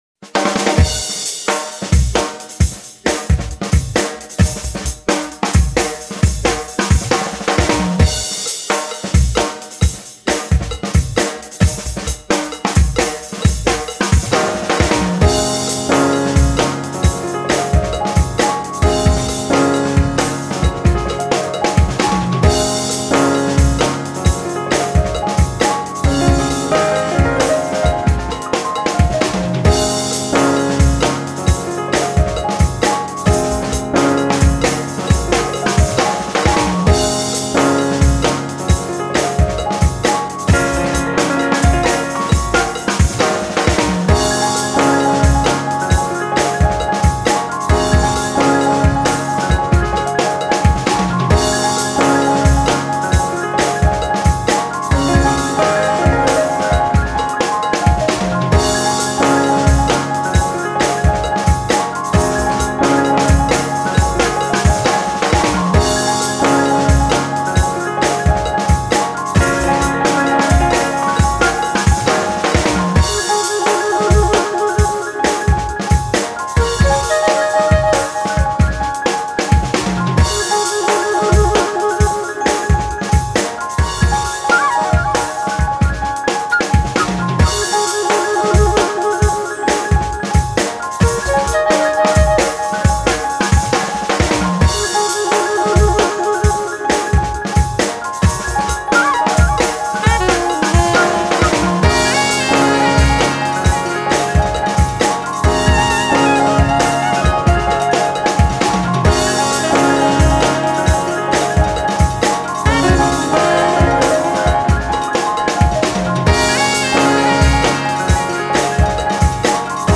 a deep, spiritual jazz record with splashes of hiphop